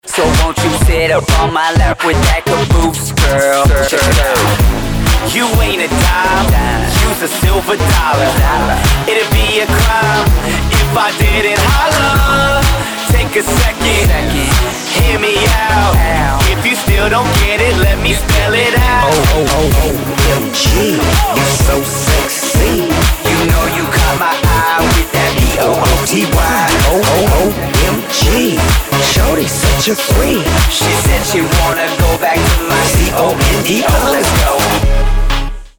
electro hip-hopová skupina